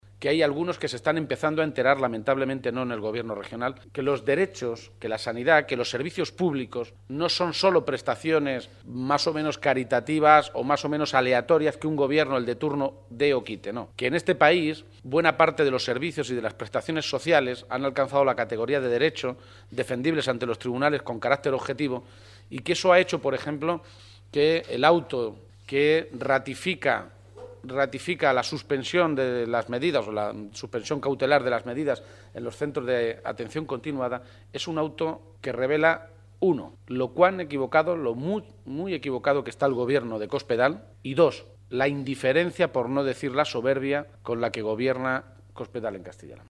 Se ha pronunciado de esta manera en una comparecencia ante los medios de comunicación en Guadalajara, poco antes de mantener una reunión con alcaldes y concejales socialistas de toda la provincia.
Cortes de audio de la rueda de prensa